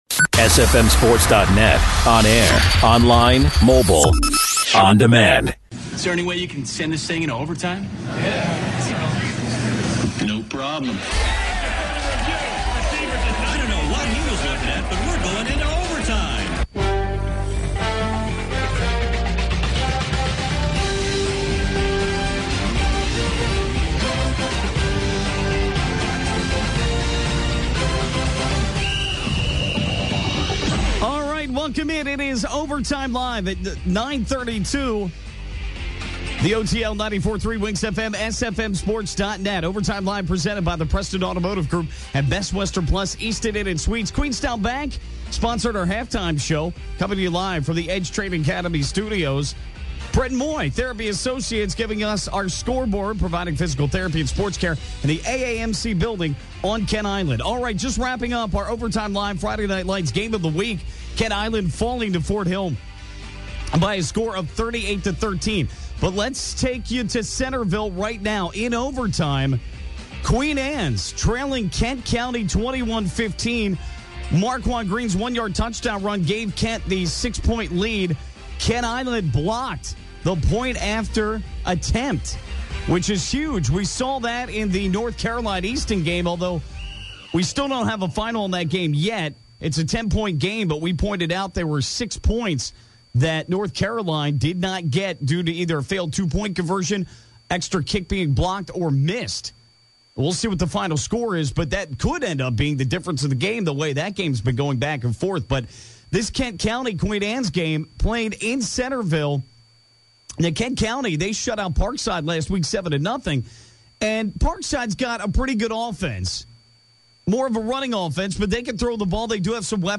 chats with the coaches and recaps the high school football action from Week 7 in the Bayside Conference.